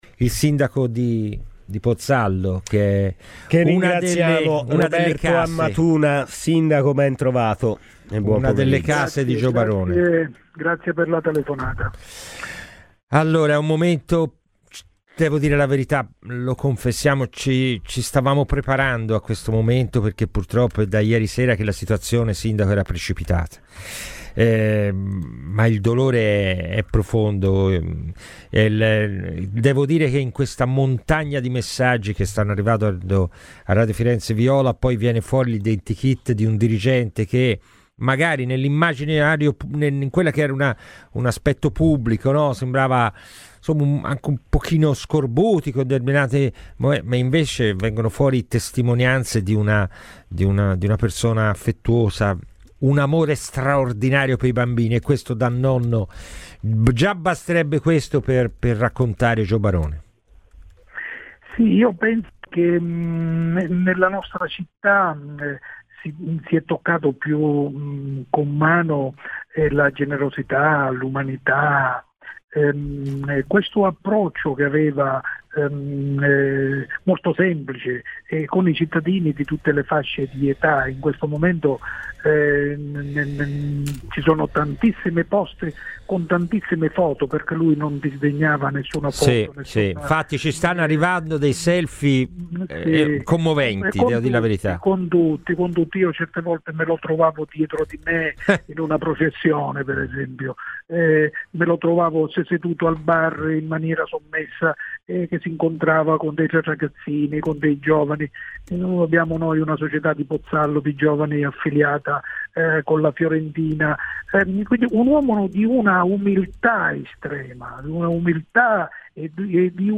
Roberto Ammatuna, sindaco di Pozzallo, è intervenuto a Radio Firenzeviola per ricordare il suo concittadino Joe Barone, scomparso oggi: "Un uomo di una umiltà incredibile, noi cittadini di Pozzallo non l'abbiamo conosciuto come direttore generale e uomo importante, ma come ragazzo con cui si scherzava e si poteva interloquire in qualsiasi momento.